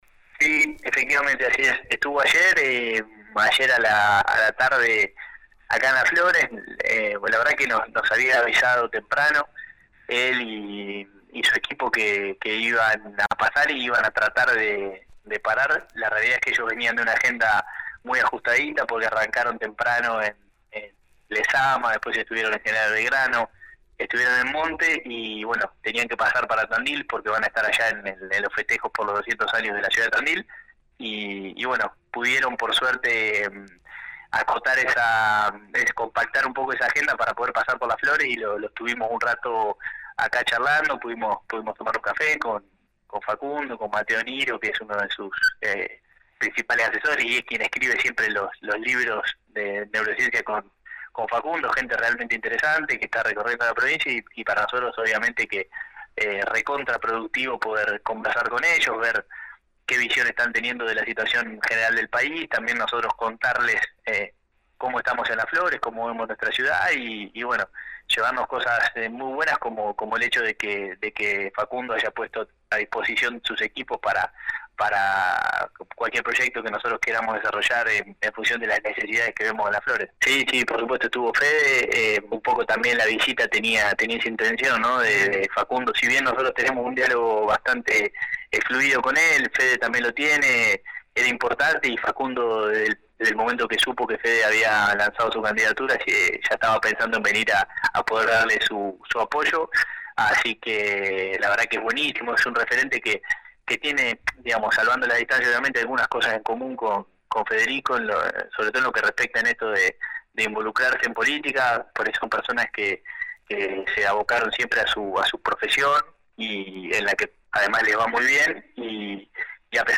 El concejal del Bloque Adelante-Juntos y dirigente radical habló este miércoles por la 91.5. Sobre la fugaz visita del precandidato a presidente por la UCR, Gennuso dijo que «se dio de repente porque Facundo viajaba rumbo a Tandil en medio de una recorrida por toda la región.